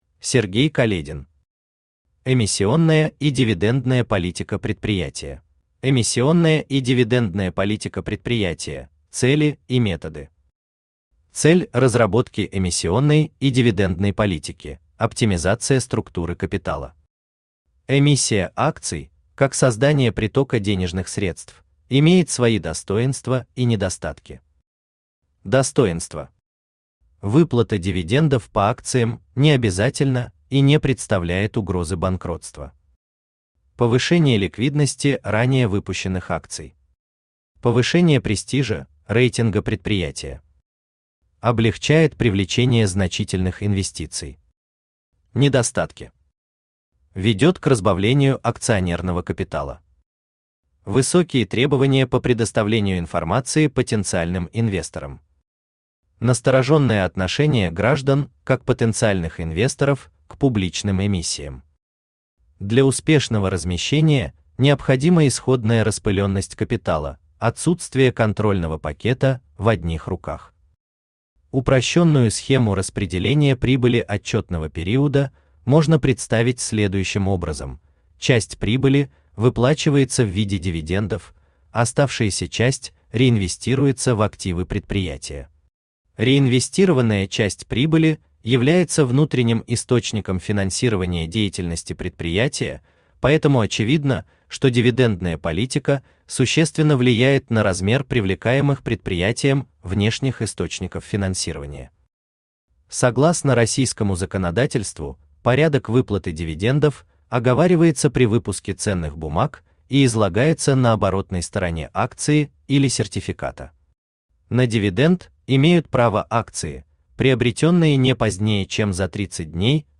Аудиокнига Эмиссионная и дивидендная политика предприятия | Библиотека аудиокниг
Aудиокнига Эмиссионная и дивидендная политика предприятия Автор Сергей Каледин Читает аудиокнигу Авточтец ЛитРес.